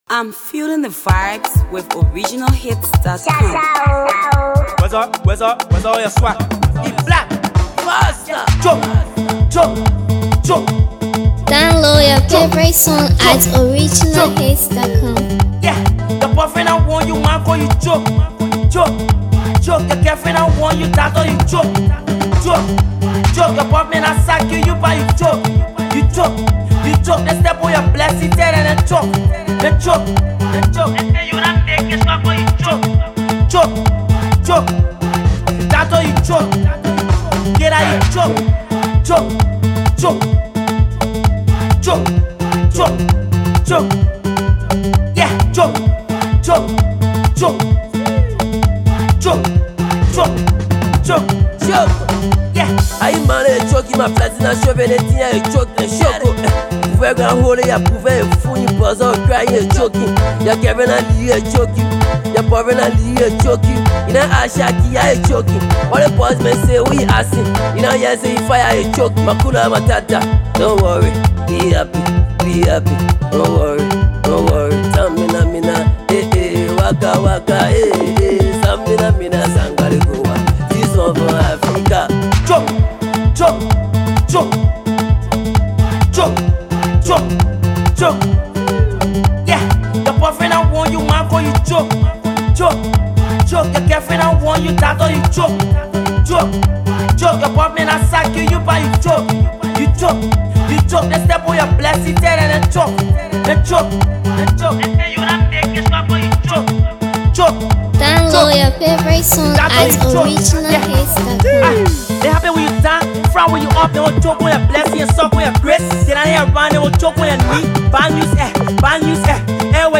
versatile rapper
Afro Pop artist